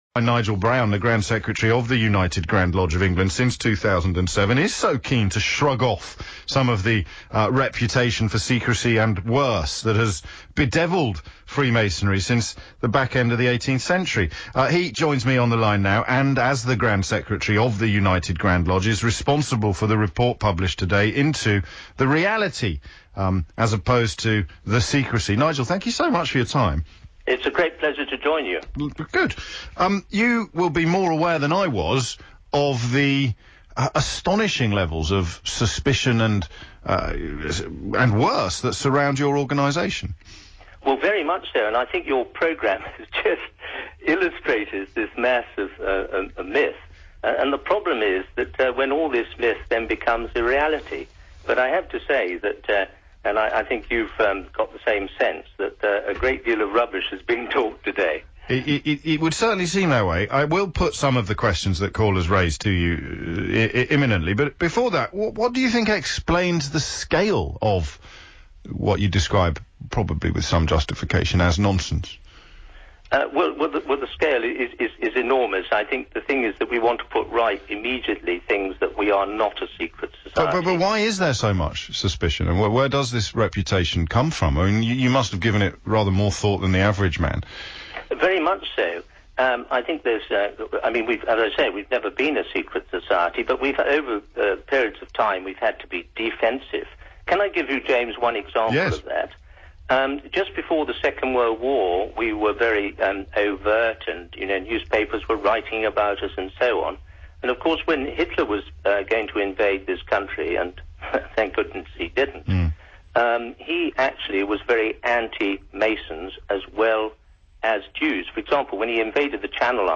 On Friday, 9th March 2012, James O'Brien dedicated the first half of his morning radio show on LBC 97.3 to Freemasonry